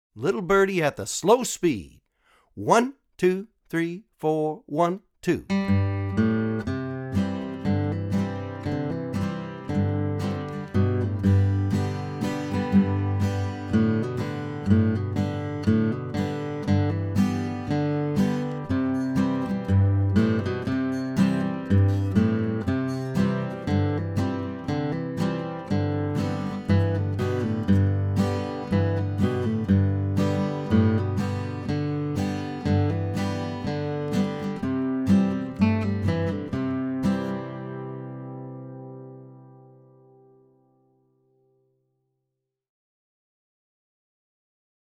DIGITAL SHEET MUSIC - FLATPICK GUITAR SOLO
Online Audio (both slow and regular speed)